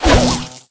jess_throw_01.ogg